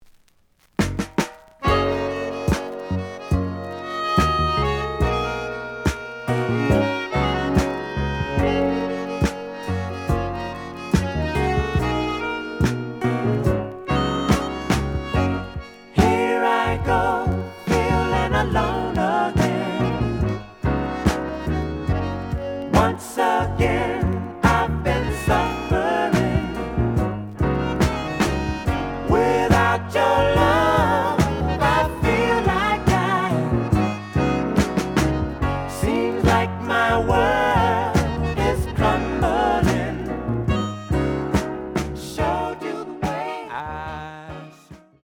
The audio sample is recorded from the actual item.
●Genre: Soul, 70's Soul
Edge warp.